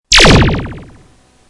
Download Laser Zap sound effect for free.
Laser Zap